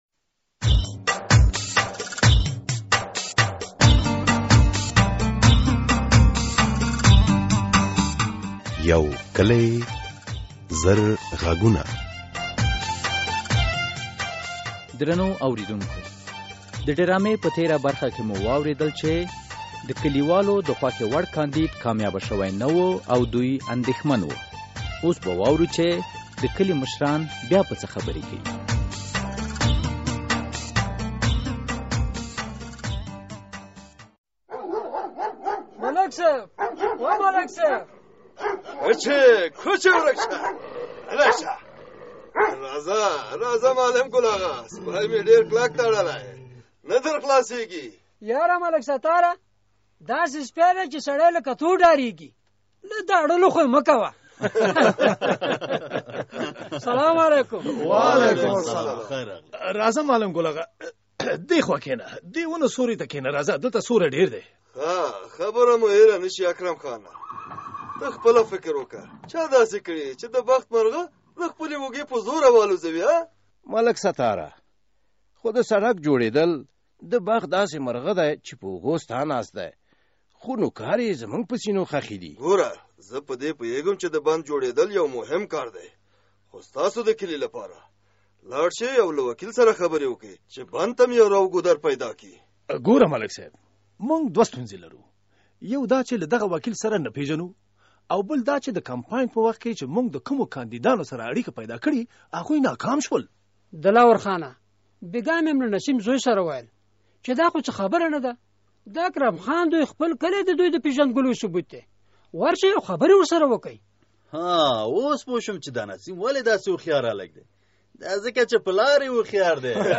یوکلي او زرغږونه ډرامه هره اونۍ د دوشنبې په ورځ څلور نیمې بجې له ازادي راډیو خپریږي.